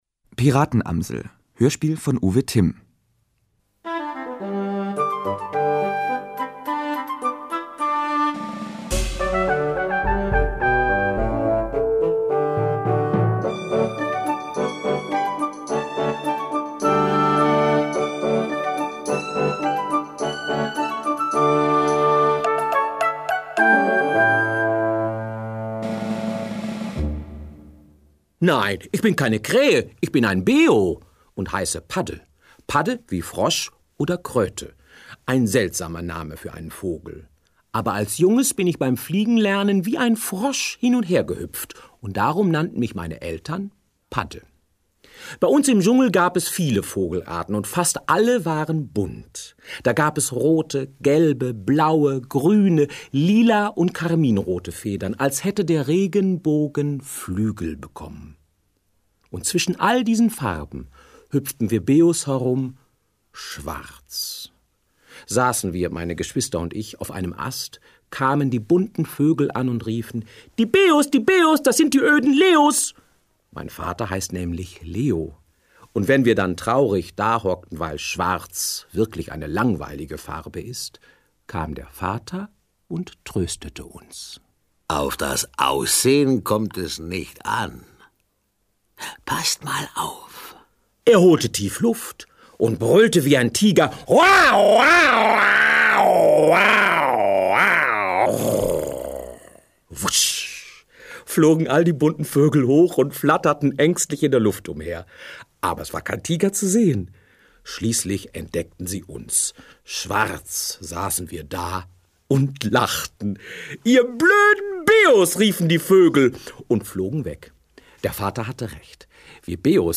Die Piratenamsel Hörspiel